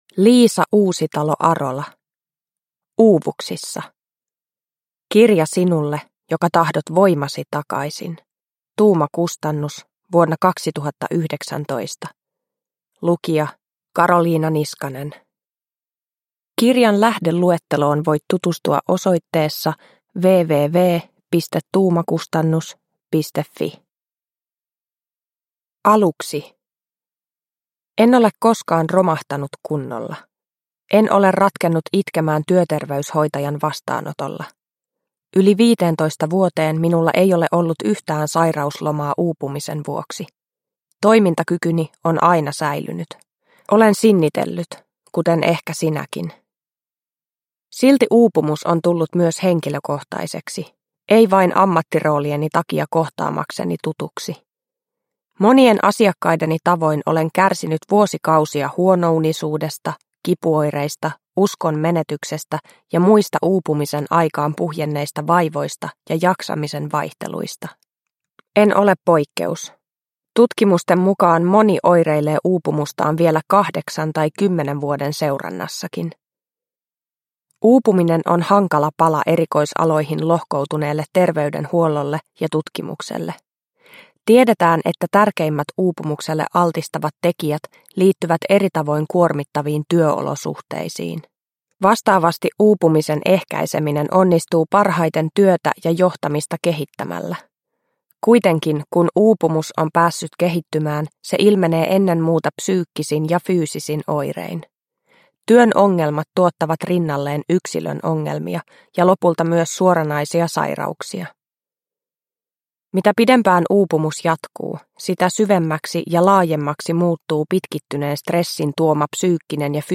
Uuvuksissa – Ljudbok – Laddas ner